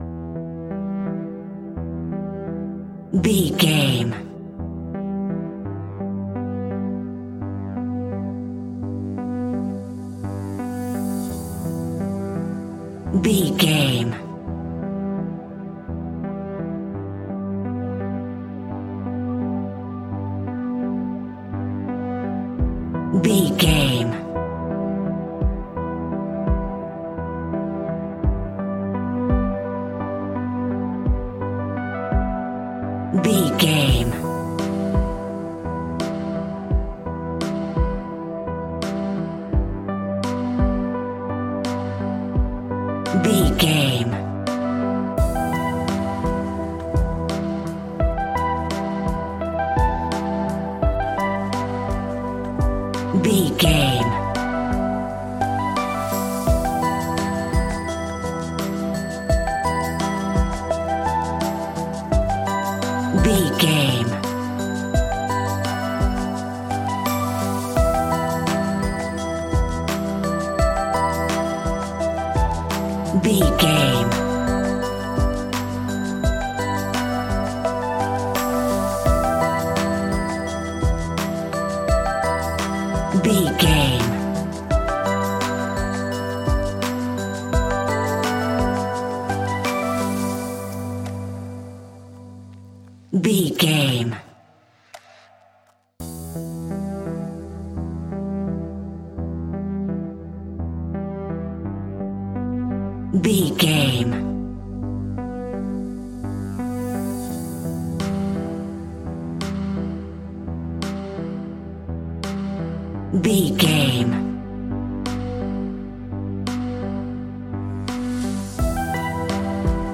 Aeolian/Minor
hip hop
royalty free hip hop music
chilled
laid back
groove
hip hop drums
hip hop synths
piano
hip hop pads